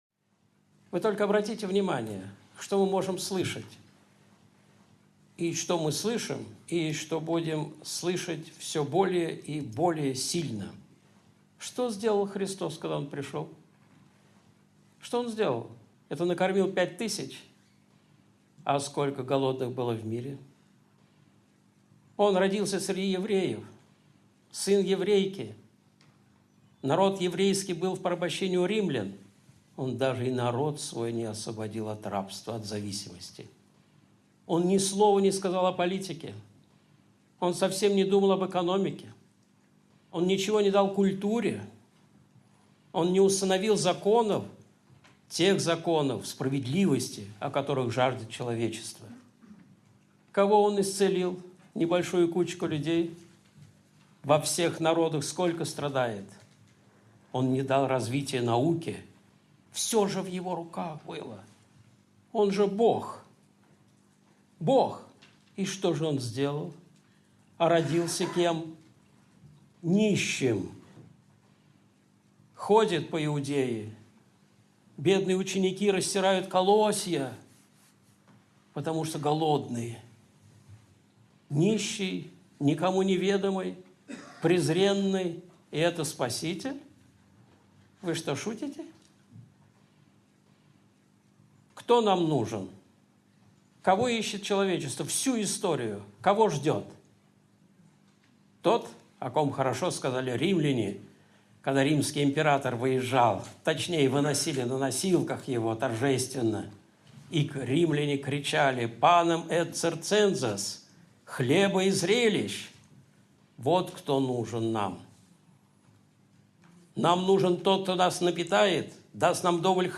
Видеолекции